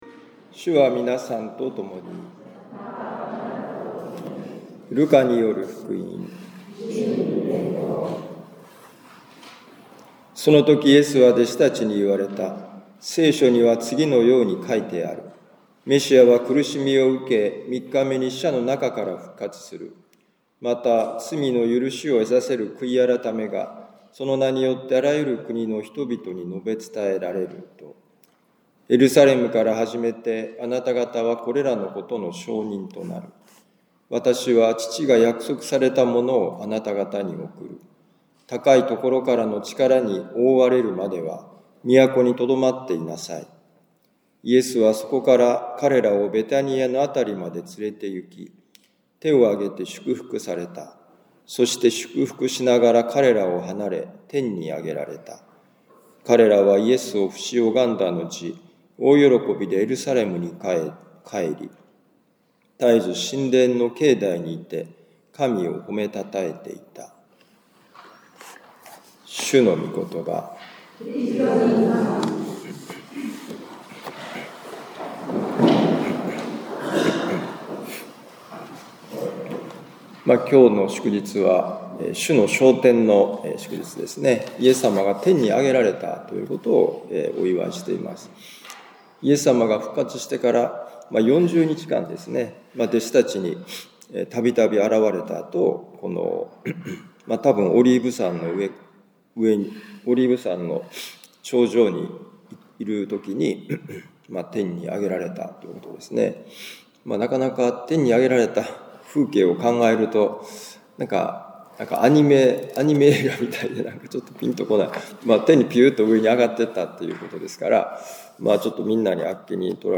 ルカ福音書24章46-53節「神様の大きさを知る」2025年6月1日主の昇天のミサ カトリック長府教会